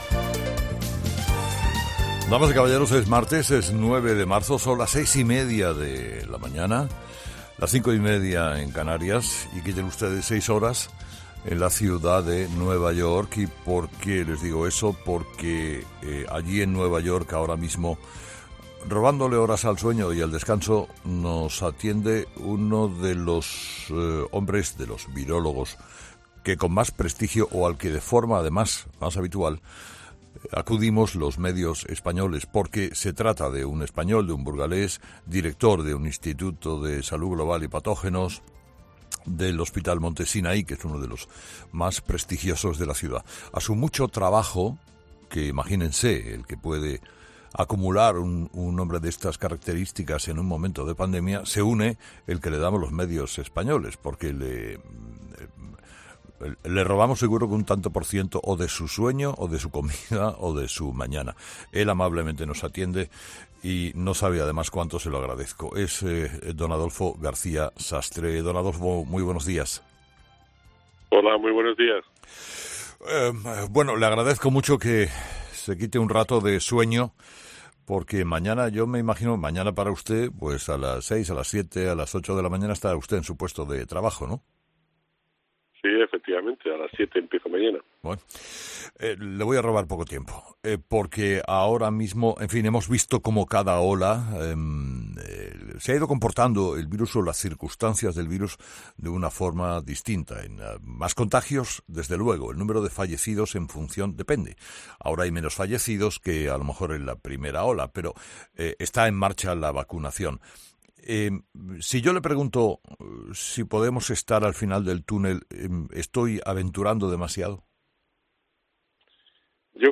El jefe de patógenos emergentes del Hospital Monte Sinaí de Nueva York ha sido entrevistado en 'Herrera en COPE' para analizar la actual situación...